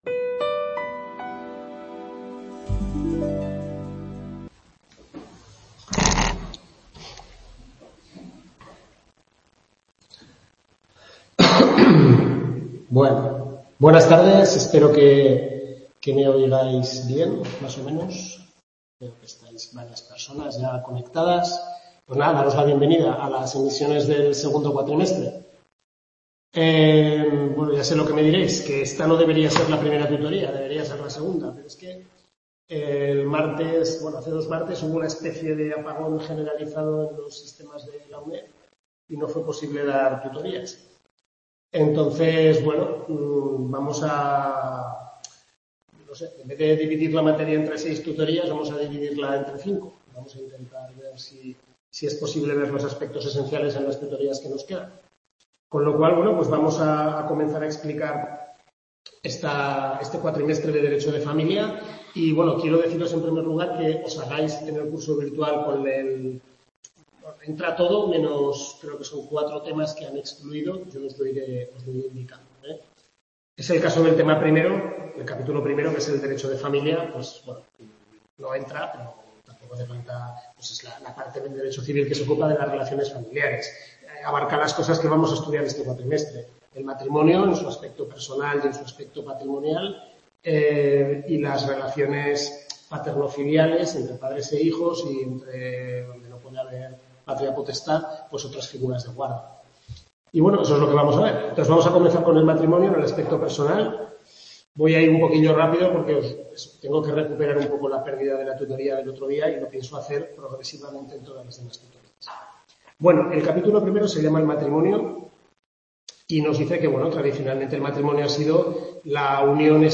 Tutoría 1/5 Civil I, Derecho de Familia, centro Uned-Calatayud, capítulos 2-7 del Manual del Profesor Lasarte